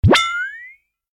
sfx
LB_evidence_hit.ogg